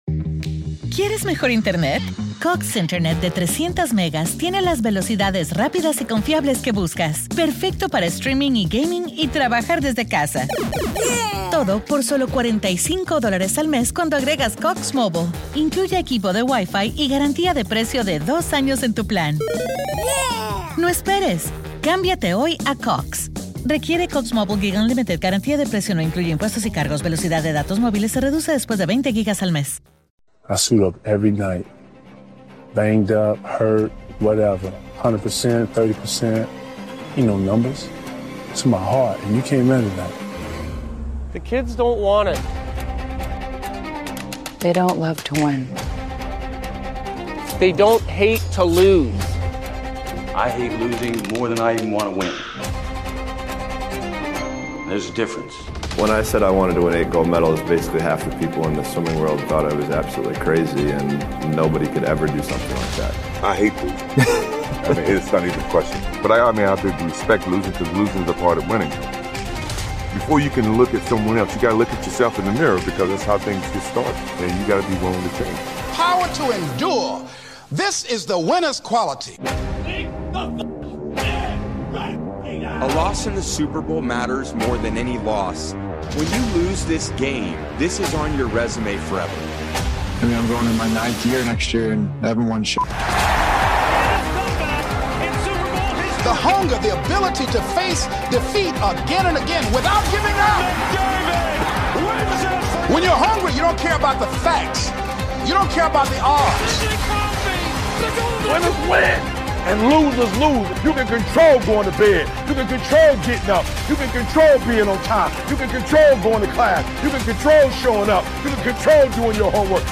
Best Motivational Speeches from Motiversity, featuring speeches from Lebron James, Kobe Bryant, Mike Tyson, Tom Brady, Serena Williams, and more.